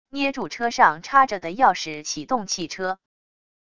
捏住车上插着的钥匙启动汽车wav音频